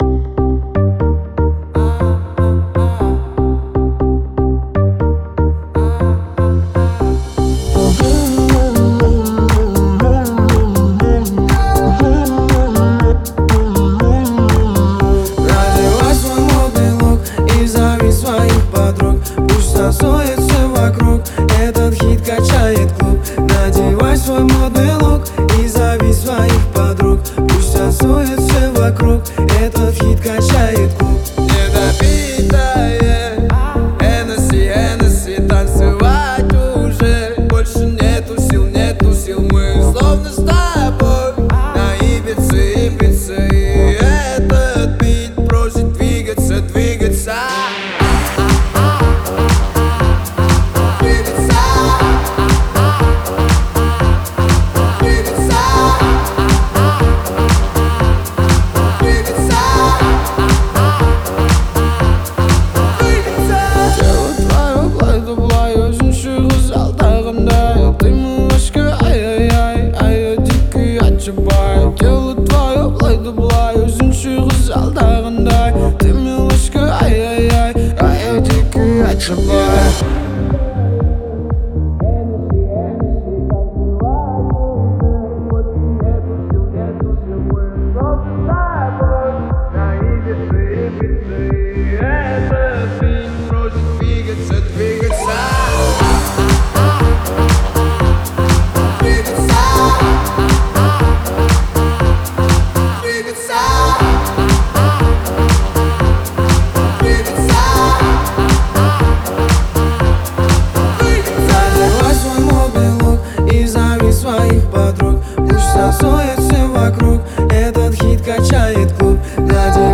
Звучание выделяется яркими синтезаторами и ритмичными битами